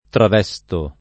travesto [ trav $S to ]